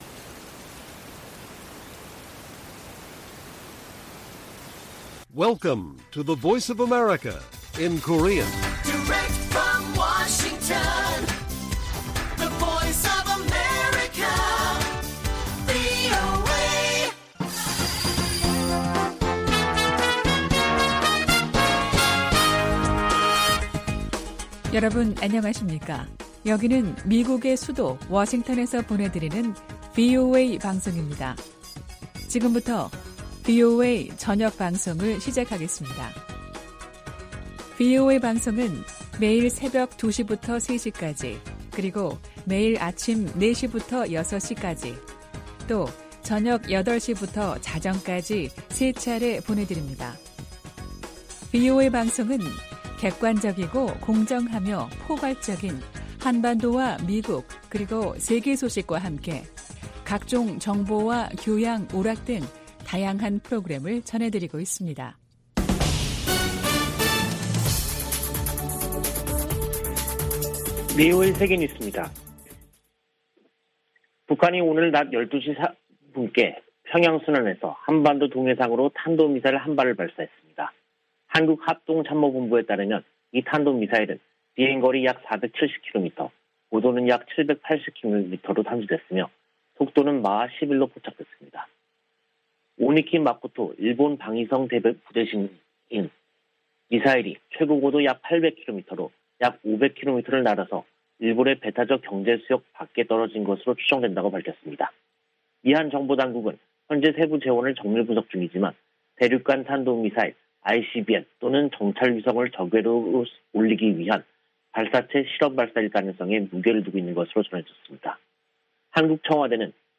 VOA 한국어 간판 뉴스 프로그램 '뉴스 투데이', 2022년 5월 4일 1부 방송입니다. 북한이 한국 새 정부 출범을 앞두고 또 다시 탄도미사일을 발사했습니다. 미국은 북한의 대륙간탄도미사일(ICBM) 도발에 대한 새 유엔 안보리 결의를 위해 논의하고 있다고 밝혔습니다. 세계 여러 나라가 안보리 대북 제재 조치 등을 즉각 수용할 수 있는 법적 체계를 갖추지 못했다는 자금세탁방지기구의 평가가 나왔습니다.